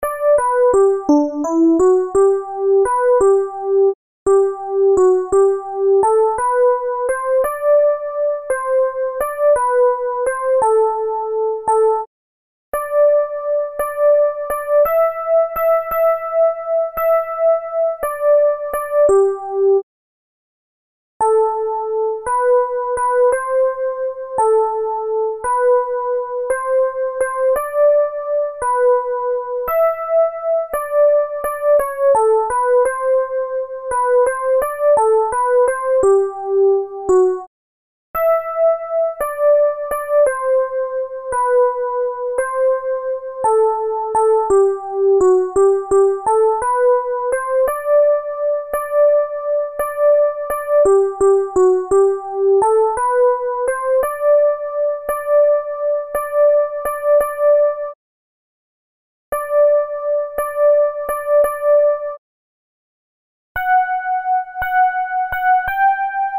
Soprani
giovani_liete_soprani.MP3